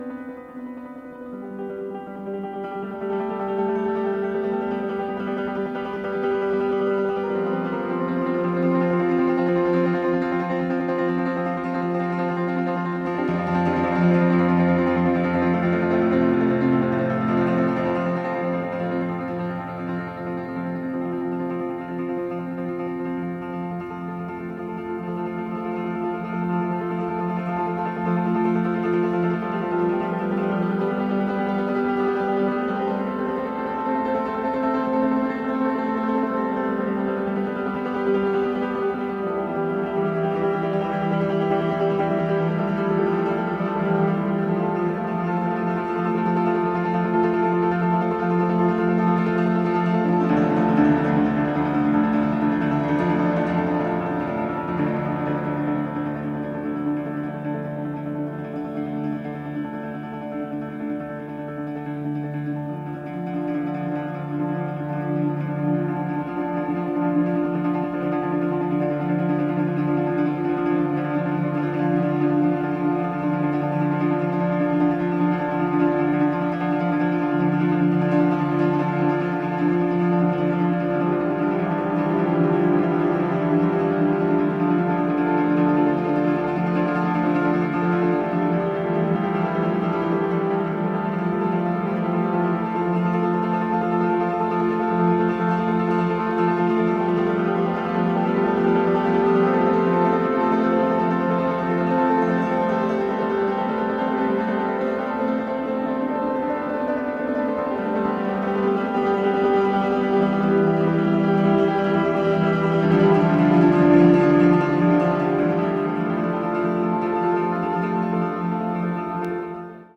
大傑作！暴力的な反復音響の連続！呪術的で神聖な風合いさえも感じられ強烈な作品！
アンプリファイされ、ディレイをかけた2台のピアノが織り成す怒涛のトレモロが、輝く倍音の宇宙を創出！